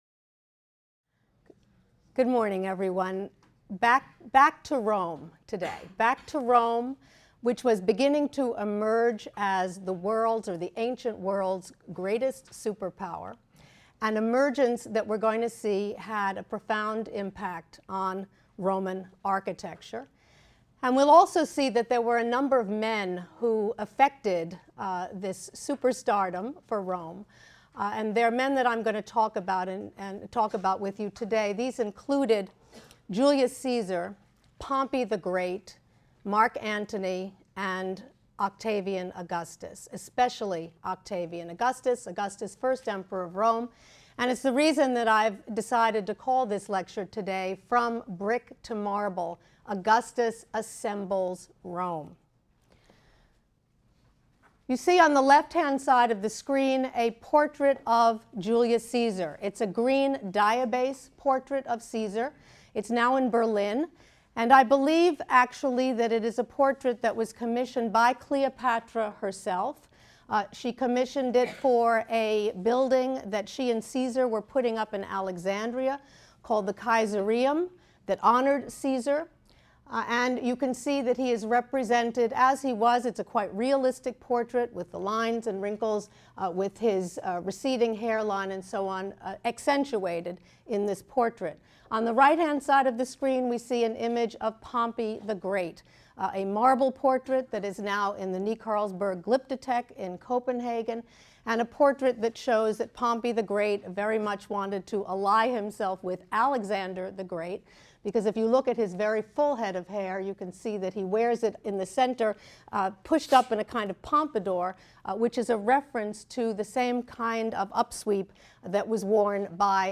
HSAR 252 - Lecture 9 - From Brick to Marble: Augustus Assembles Rome | Open Yale Courses